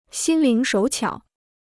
心灵手巧 (xīn líng shǒu qiǎo): capable; clever.